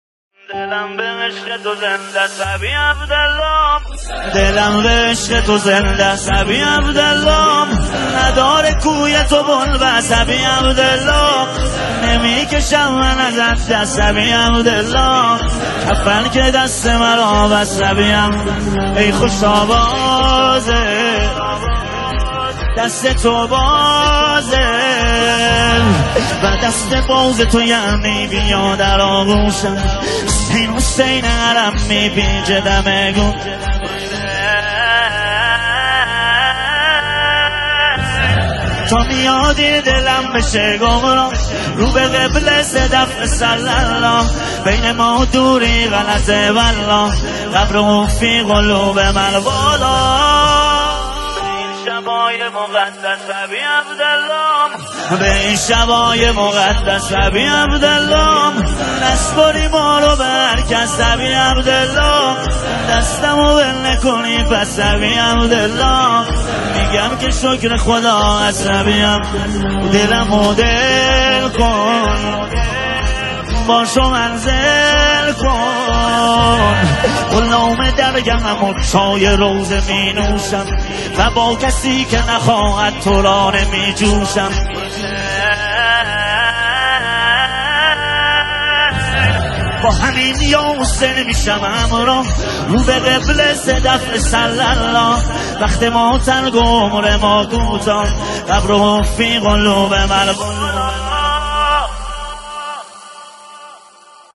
مداحی ماه محرم
مداحی امام حسین